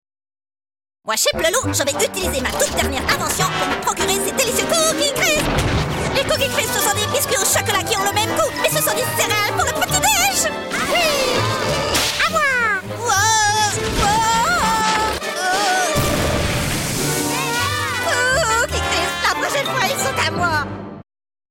Publicité & Doublage Voix Avec une voix à la fois chaleureuse